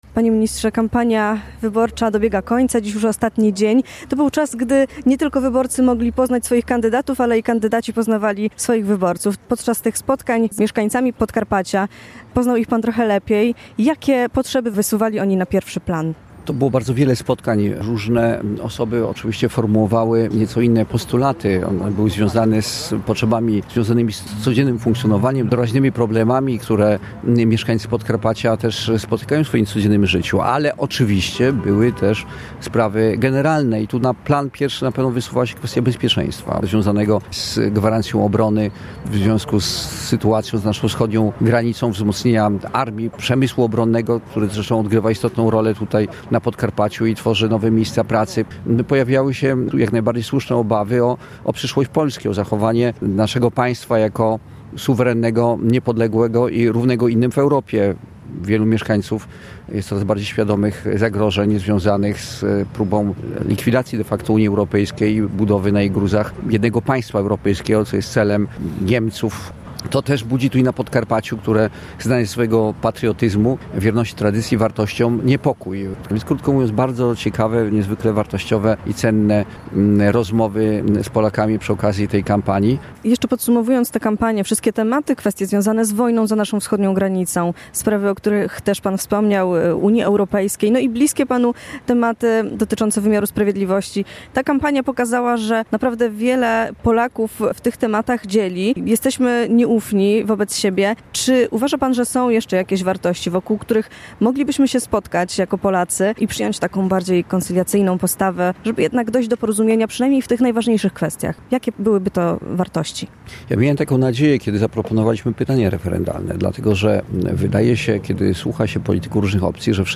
Minister Sprawiedliwości i Prokurator Generalny Zbigniew Ziobro był także gościem w popołudniowej audycji Puls Dnia, gdzie podsumował kampanię tegorocznych wyborów parlamentarnych.
Rozmowa-z-Z.Ziobro.mp3